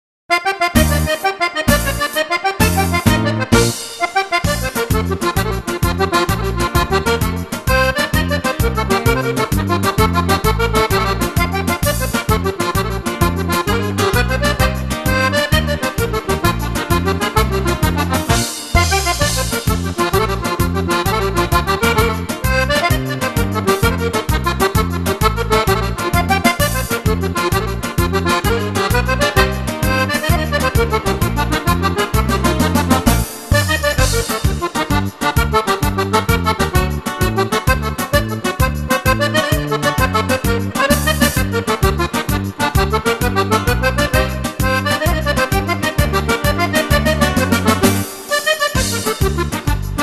Allegro
ballabili per fisarmonica e orchestra